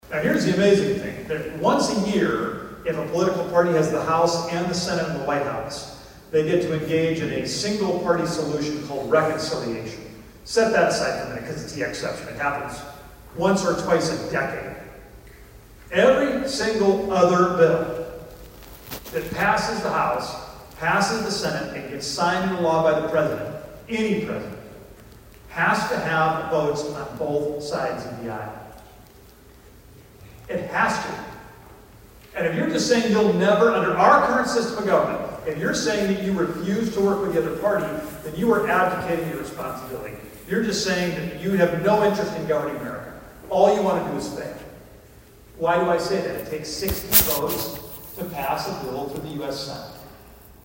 ABERDEEN, S.D.(HubCityRadio)- Monday kicked off the 82nd Boys State taking place at Northern State University in Aberdeen.